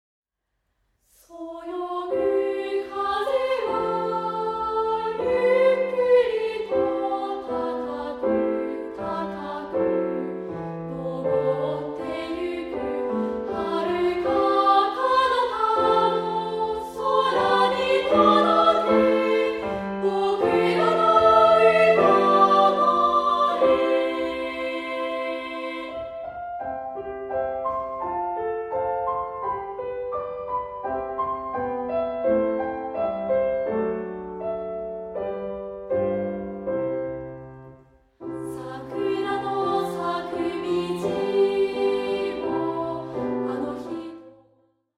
範唱＋カラピアノCD付き
2部合唱／伴奏：ピアノ